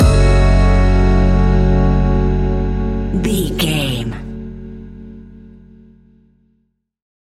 Ionian/Major
F♯
laid back
Lounge
sparse
chilled electronica
ambient
atmospheric